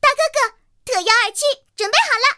T-127编入语音.OGG